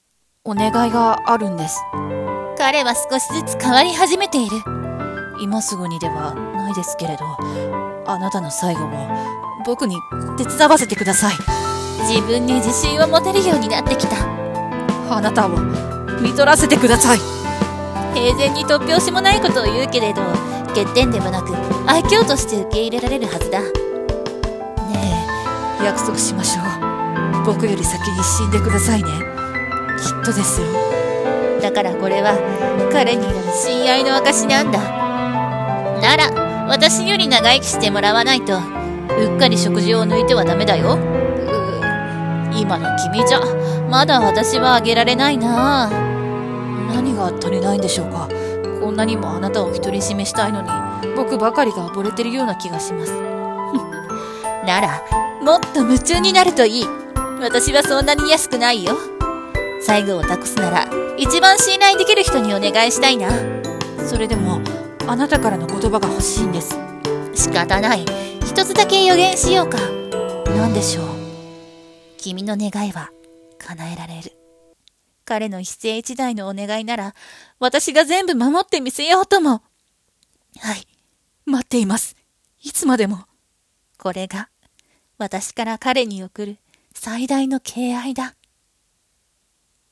【声劇台本】言葉を紡ぐ貴方へ敬愛を